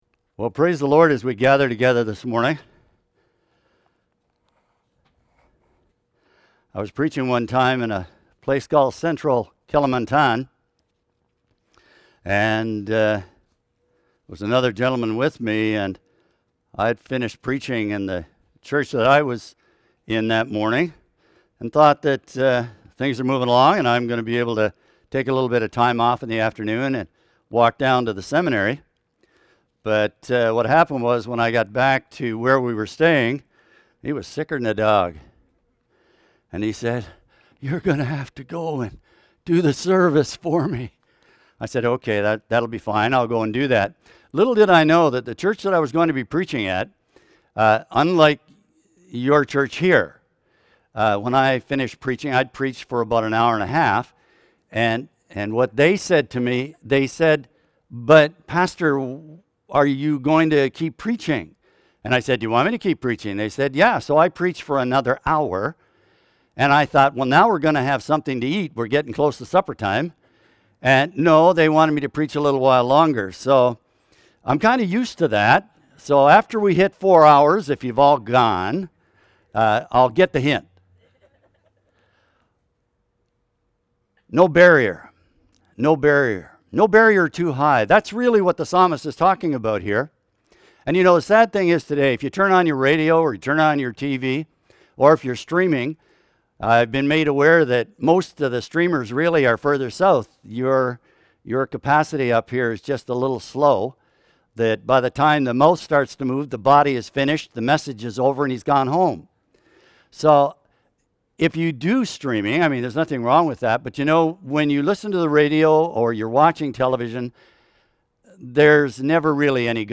John 15:18-16:4 Service Type: Sermon